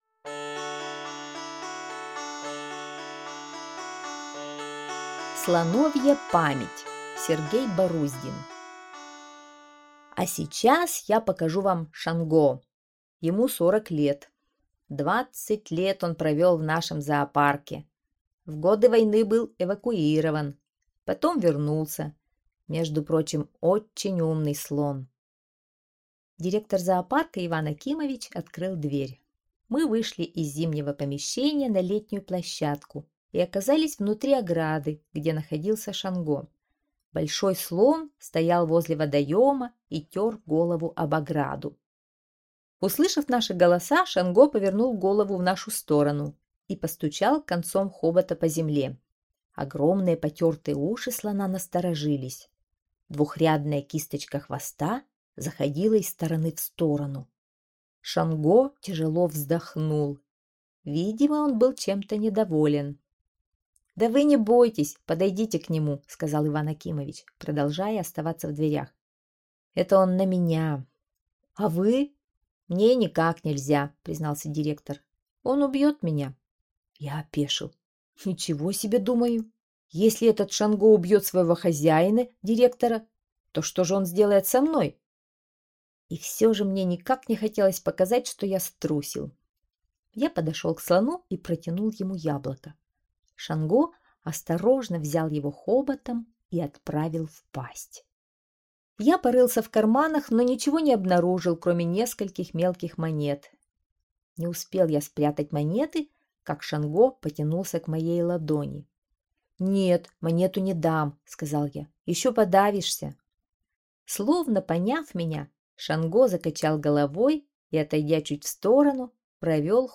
Слоновья память - аудио рассказ Баруздина - слушать онлайн